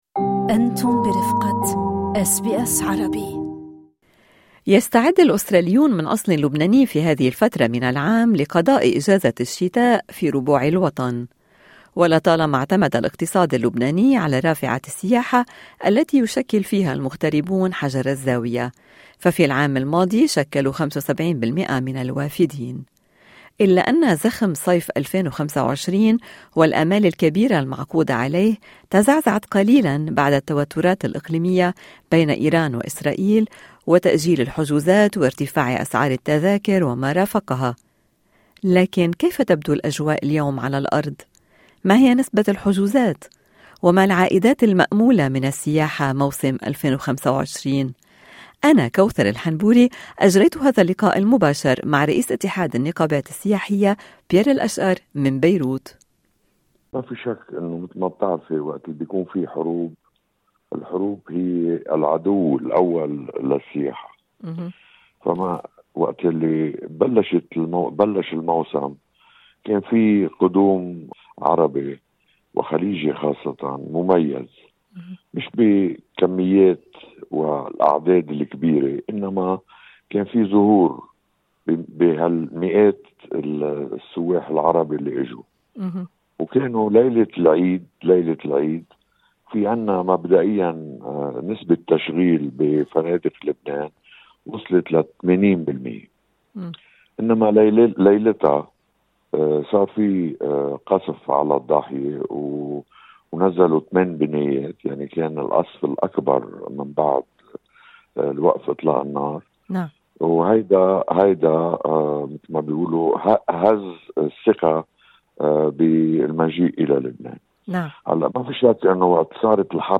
إيرادات السياحة في لبنان قد تتجاوز 4 مليار دولار "إذا تركونا نكمّل الموسم": خبير يشرح